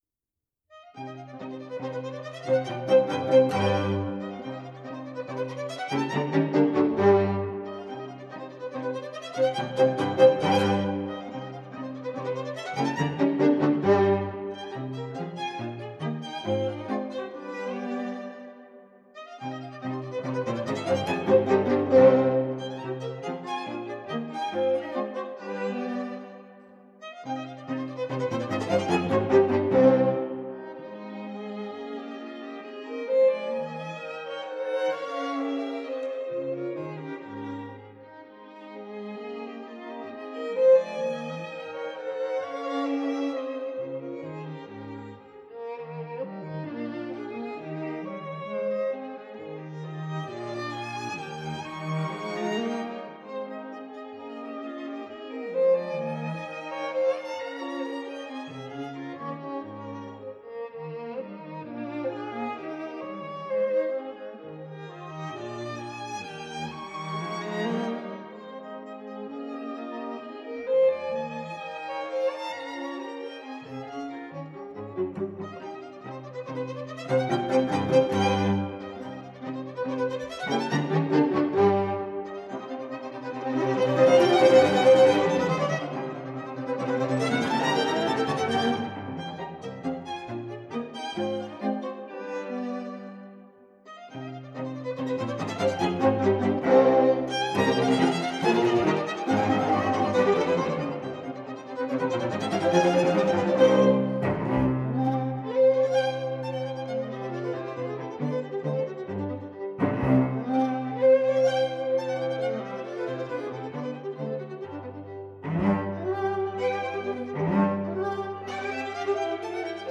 LiveARTS String Quartet